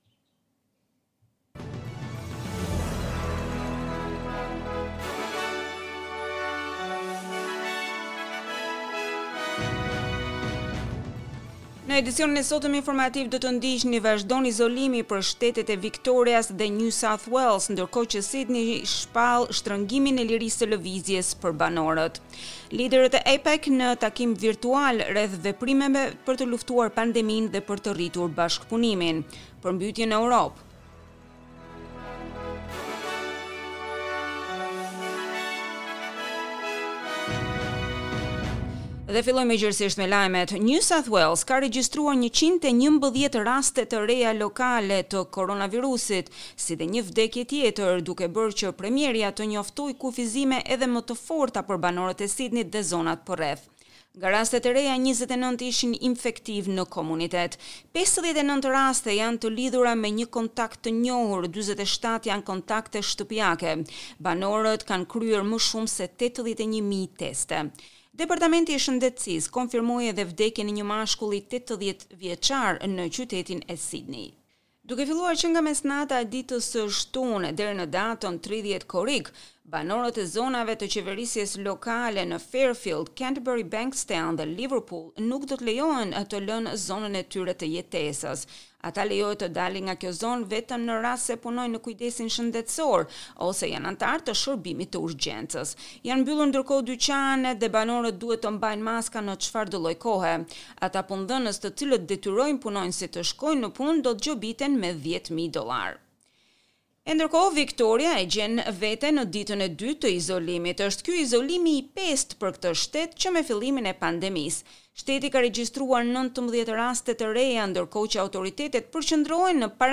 SBS News Bulletin in Albanian - 17 July 2021